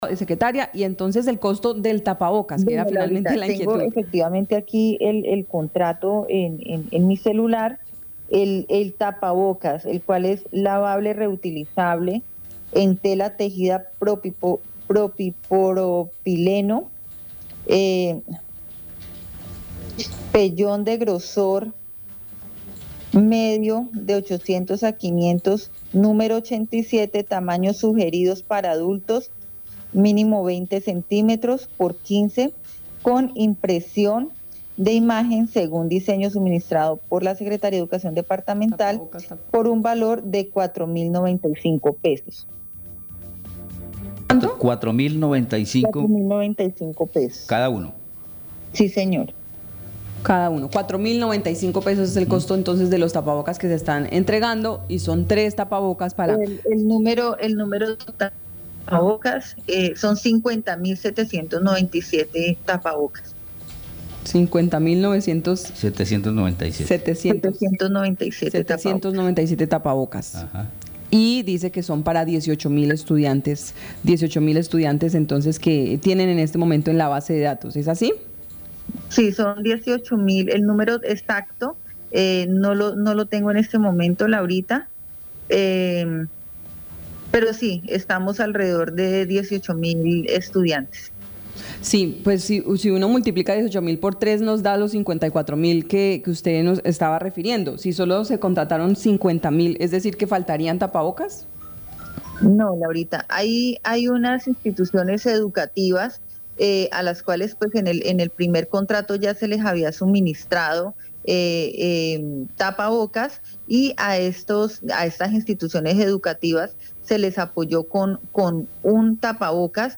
Escuche a Alexandra Rayo, secretaria de Educación del Guaviare.